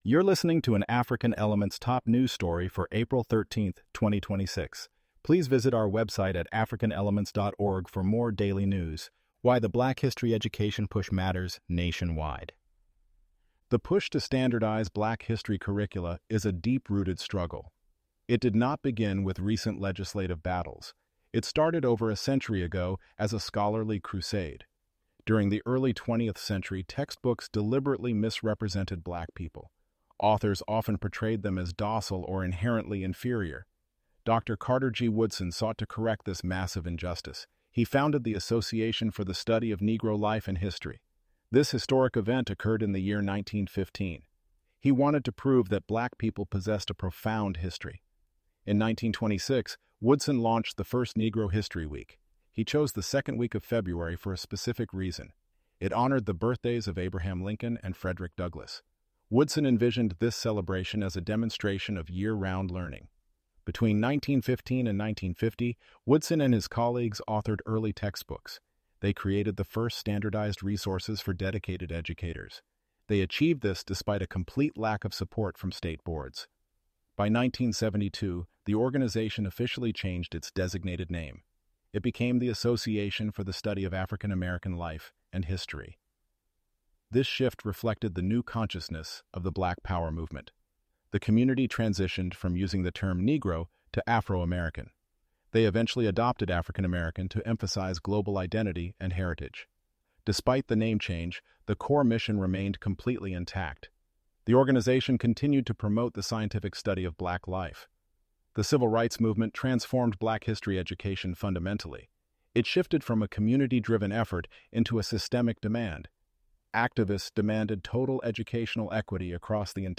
ElevenLabs_Why_The_Black_History_Education_Push_Matters_Nationwide-1.mp3